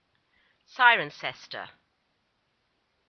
Cirencester (/ˈsrənsɛstə/
En-Cirencester.oga.mp3